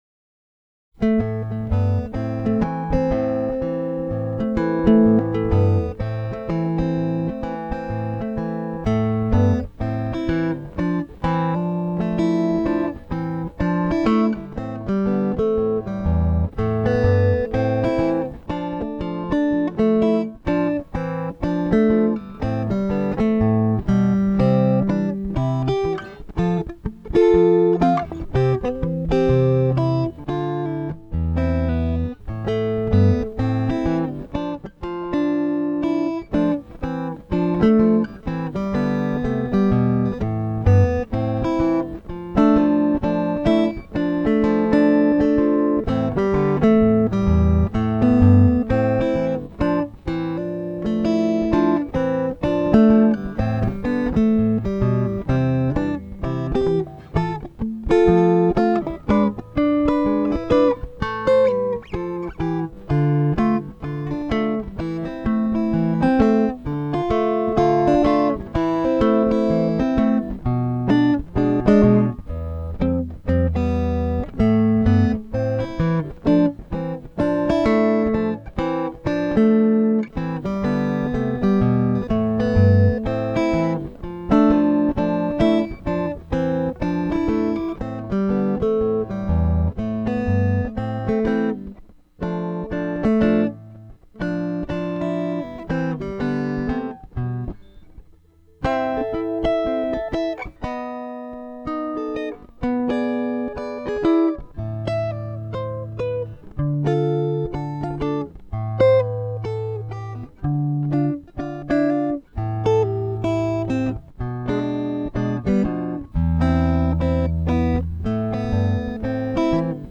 Instrumentals for Guitar
Only parts of this tune are in a foul humor.